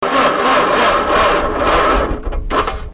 Weak battery sound
starter_weak_battery.mp3